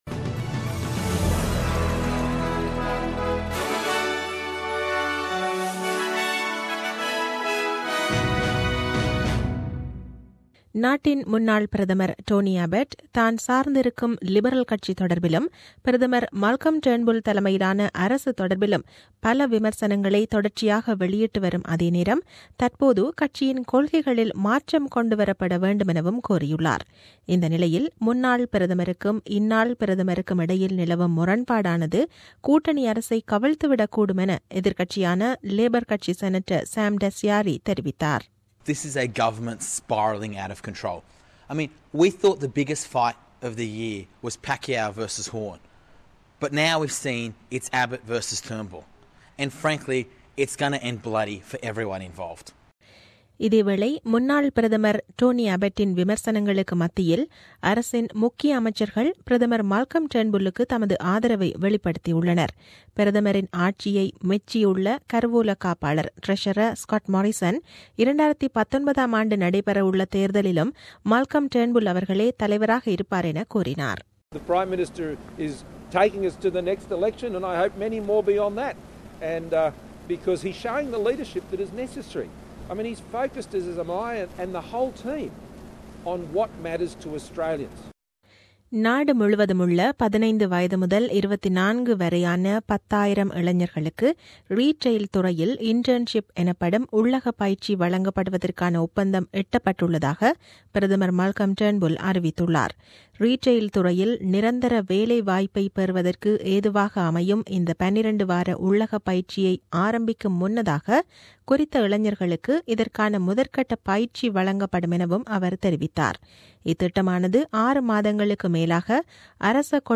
The news bulletin aired on 03 July 2017 at 8pm.